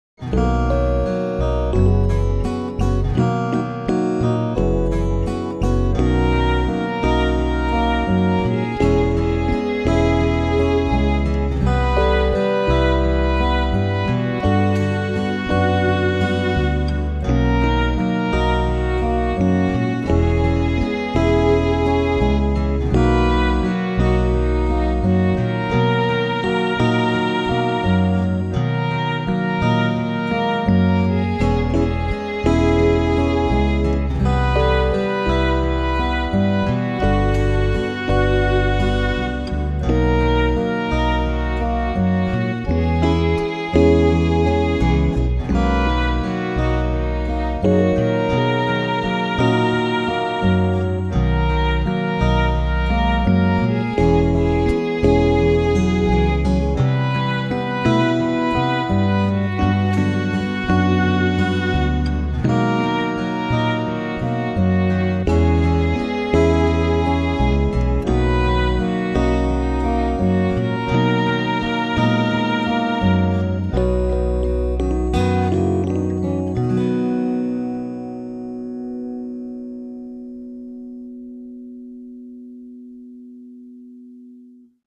All I could manage this week was a jazz style chant based on the reading from Romans 13:8-10.